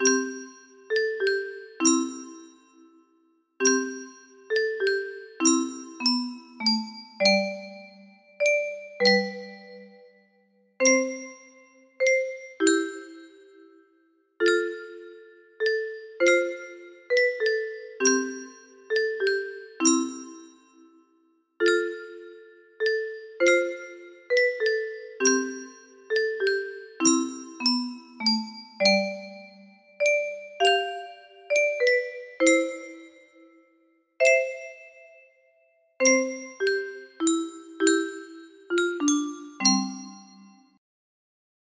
Silent night (low and sparse) music box melody